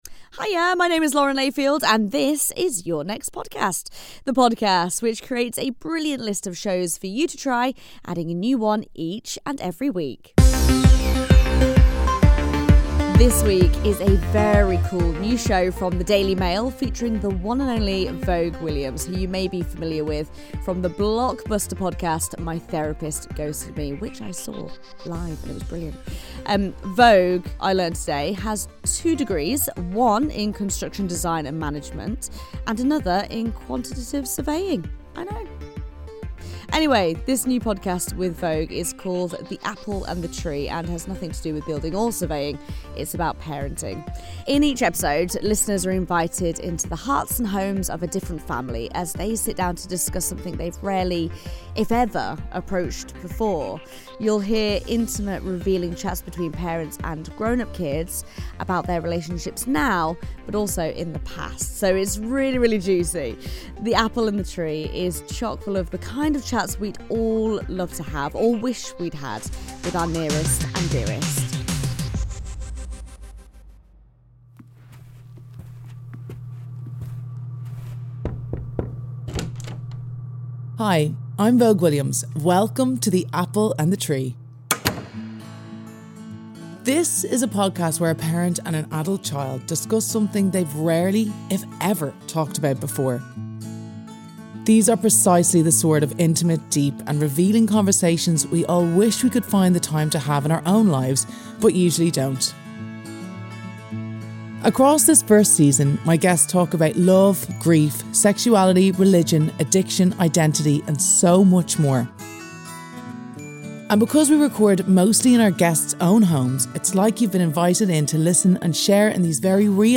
Lauren Layfield introduces The Apple and the Tree on the podcast recommendation podcast - Your Next Podcast.
These are deep, intimate and revealing conversations that move through love, grief, shame, sexuality, religion, addiction, identity and so much more. Because these shows were recorded in our guest’s own homes, it’s like you’ve been invited in to sit alongside them and hear exactly how their lives have unfurled.